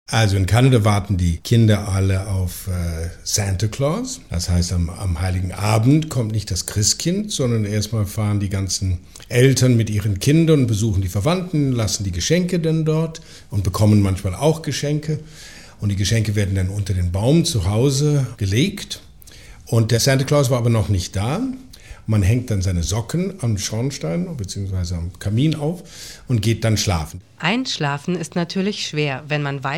Wie feiert man Weihnachten in anderen Ländern? Die Schauspieler der "Lindenstraße" kommen aus Kanada, Griechenland, Polen... und erzählen von ihren Bräuchen.